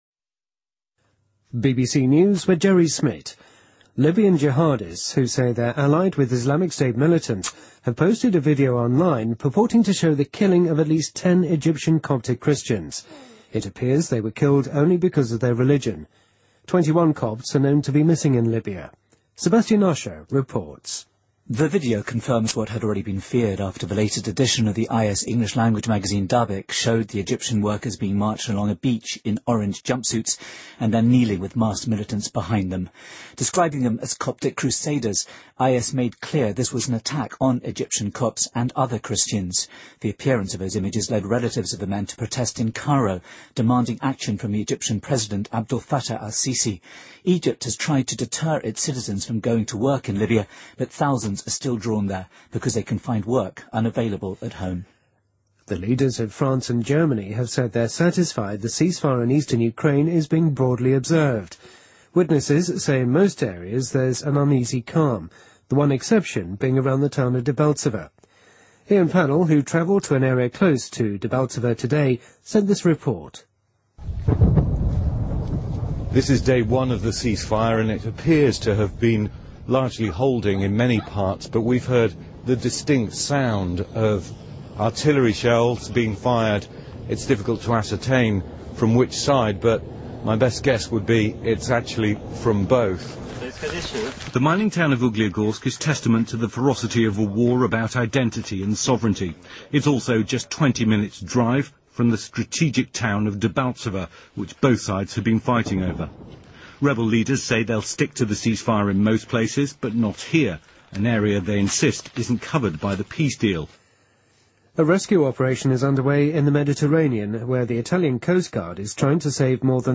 BBC news